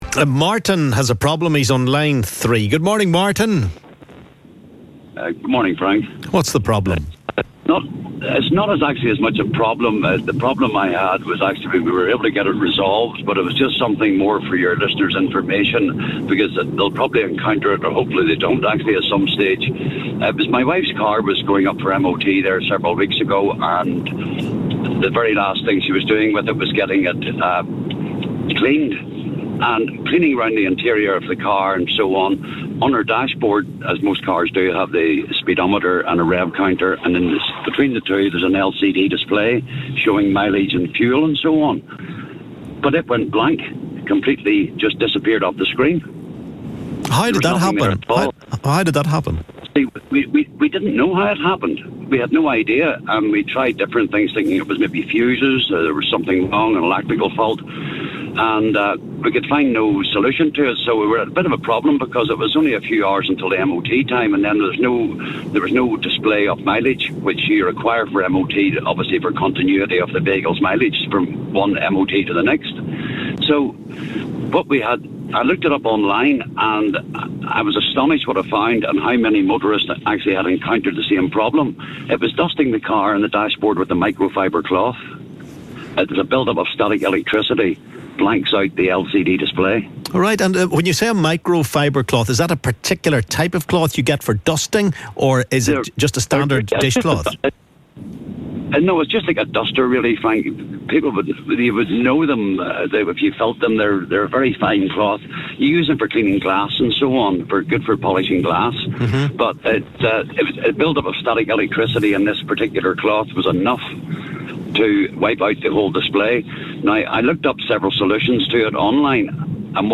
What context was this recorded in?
LISTEN ¦ Caller has warning about "wiping" your dashboard's digital displays using micro-fibre cloths